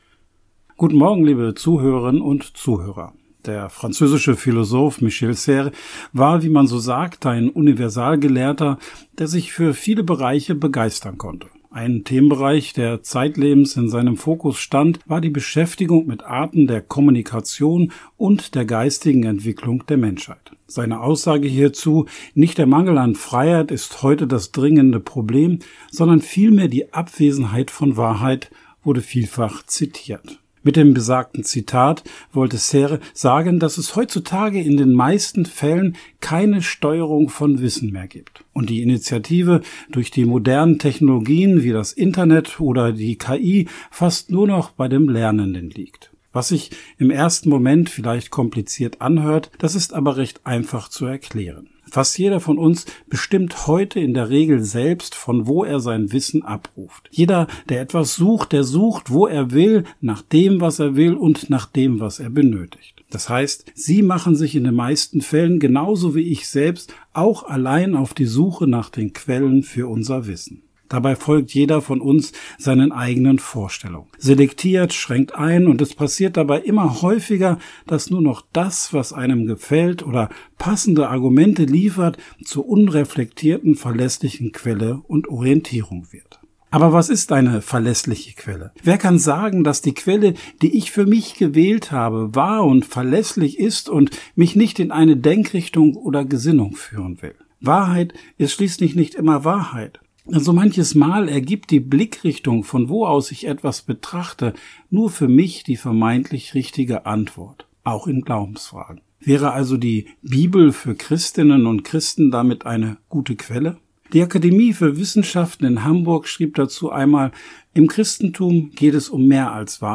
Radioandacht vom 19. Juni